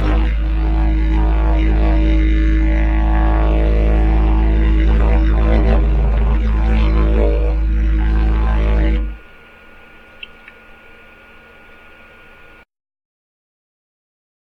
MY DIDJERIDUs
Fashioned from an Arizona-native agave trunk. Length is 6'-4", pitched in G#.